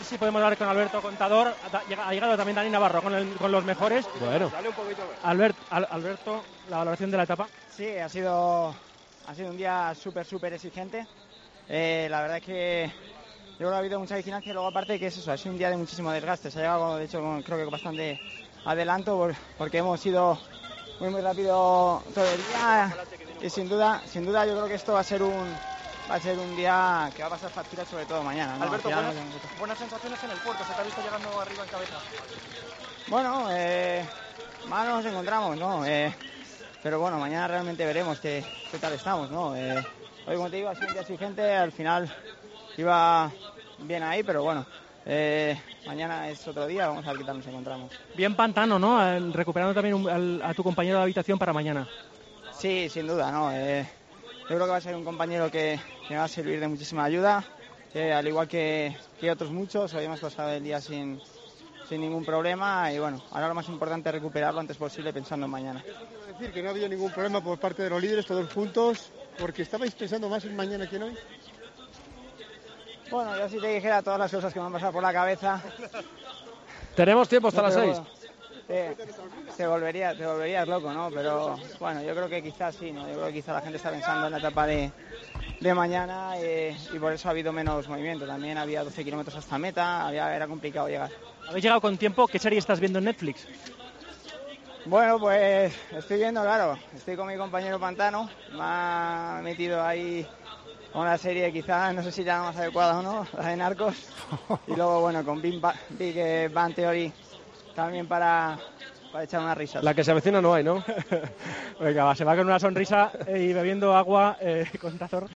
El ciclista del Trek habló con los periodistas al término de la octava etapa del Tour: "Día exigente y con mucha vigilancia.